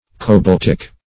Cobaltic \Co*balt"ic\ (?; 74), a. [Cf. F. cobaltique.]